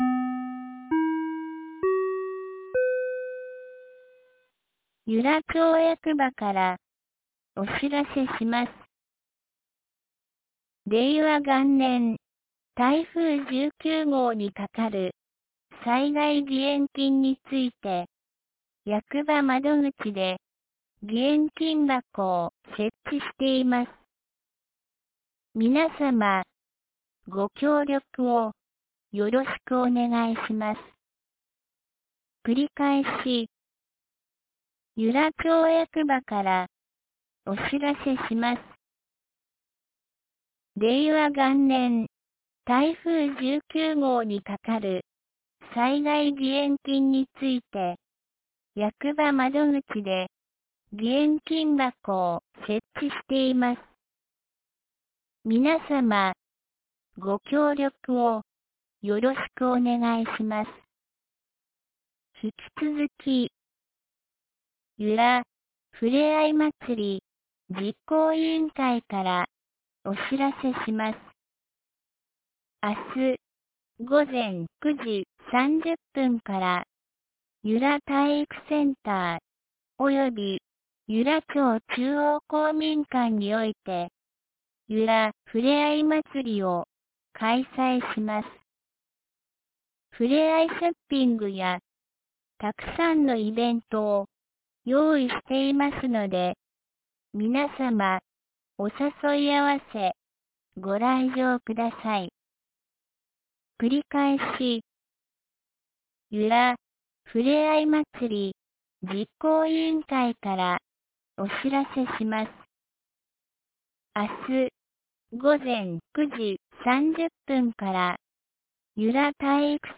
2019年11月02日 17時13分に、由良町から全地区へ放送がありました。
放送音声